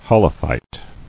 [ho·lo·phyte]